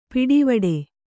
♪ piḍivaḍe